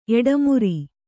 ♪ eḍamuri